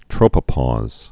(trōpə-pôz, trŏpə-)